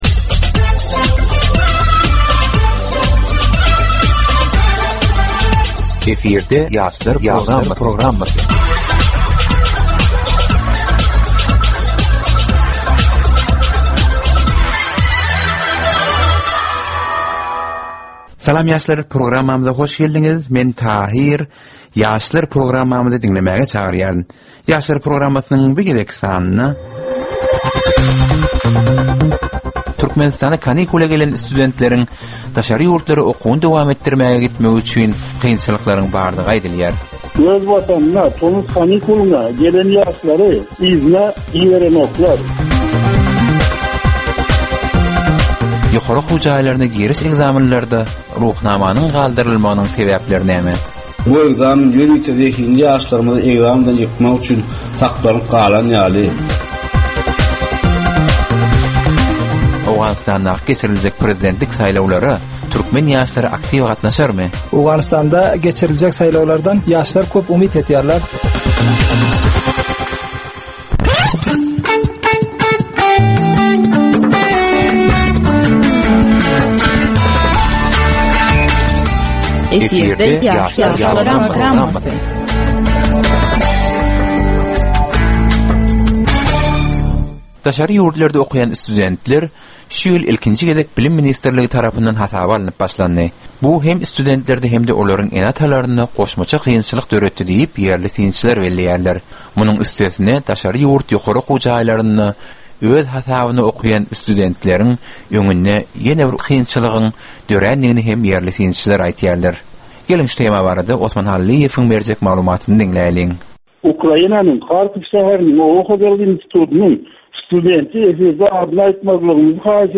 Türkmen we halkara yaslarynyn durmusyna degisli derwaýys meselelere we täzeliklere bagyslanylyp taýýarlanylýan 15 minutlyk ýörite geplesik.
Geplesigin dowmynda aýdym-sazlar hem esitdirilýär.